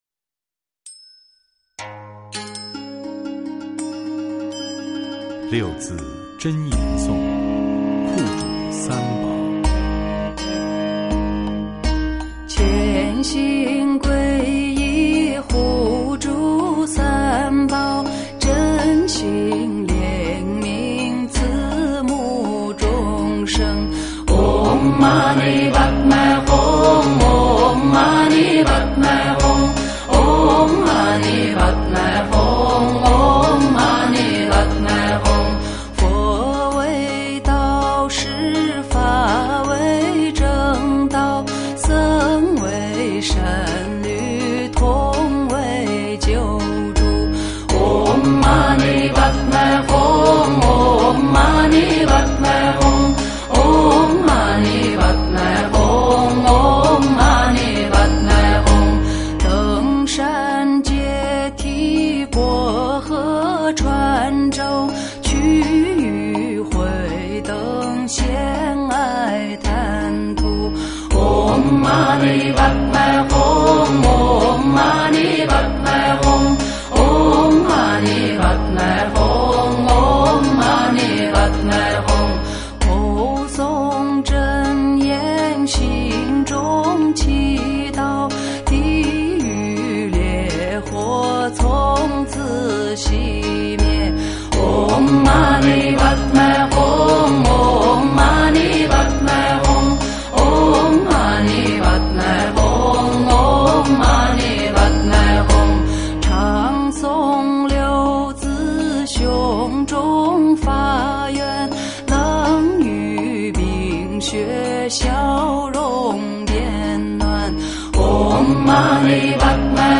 类型：佛教咒语